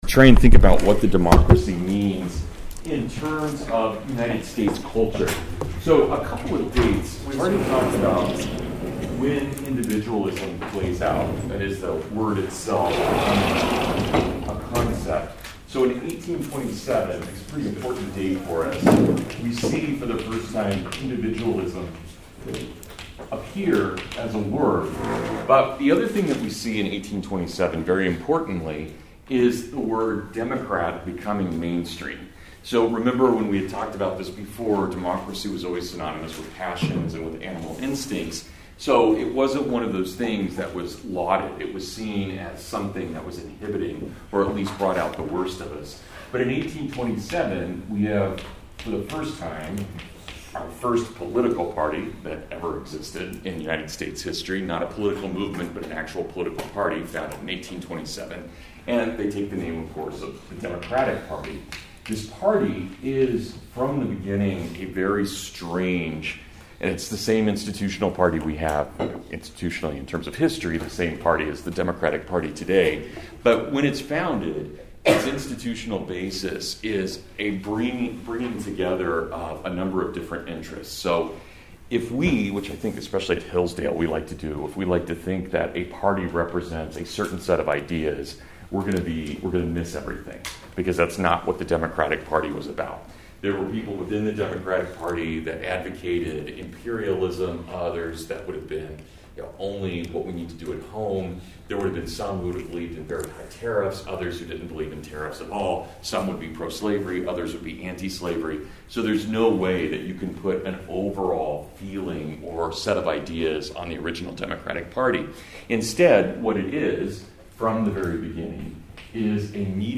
The origins of the Democratic Party, the unconstitutionality of parties, and the arrival of Manifest Destiny. Full lecture.